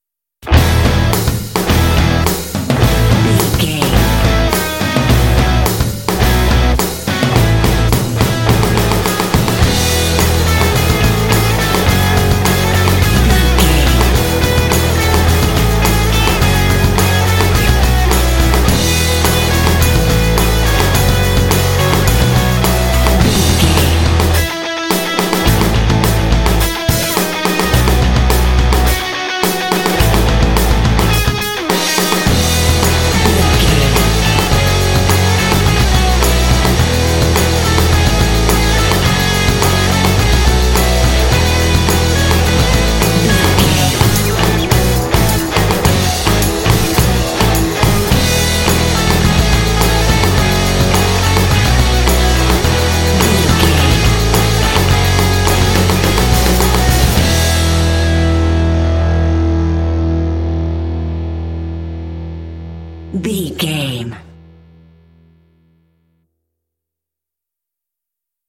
This rock track is great for action and sports games.
Epic / Action
Uplifting
Ionian/Major
driving
determined
energetic
lively
electric guitar
bass guitar
drums
alternative rock
indie